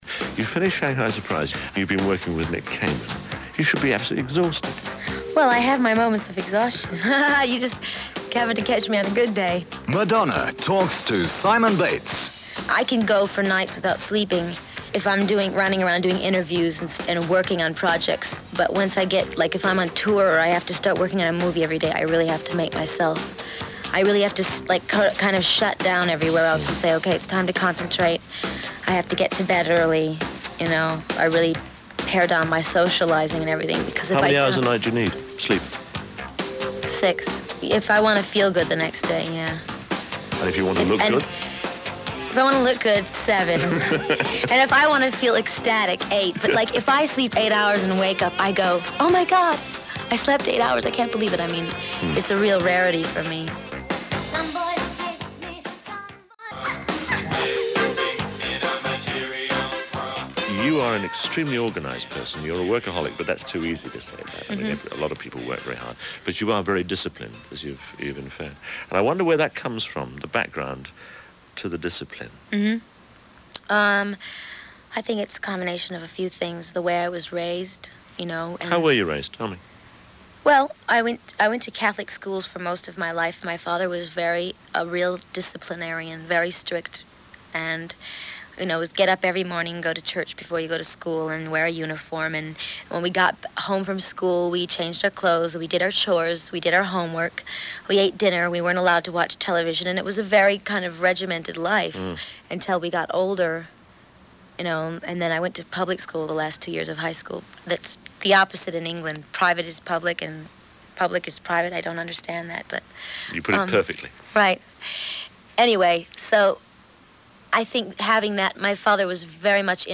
Simon Bates in the eighties on Radio 1- chatting casually to a 28 year old Madonna.